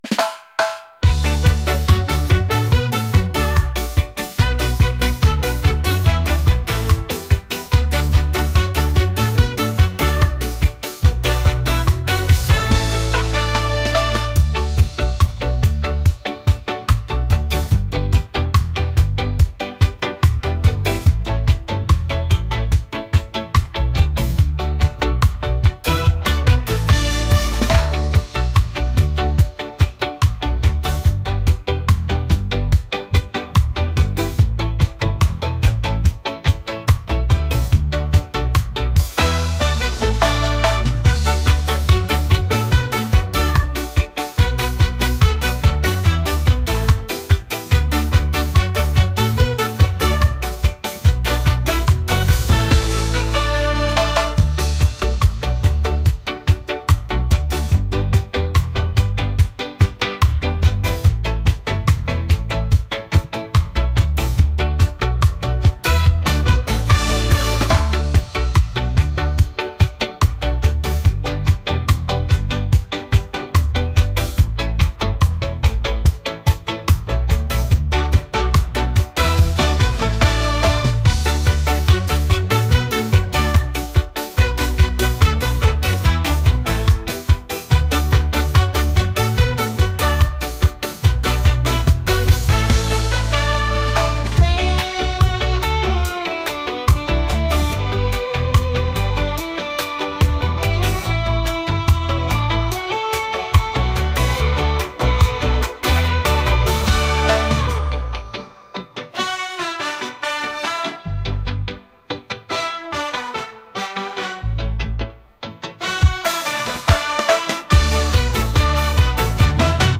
reggae | pop | world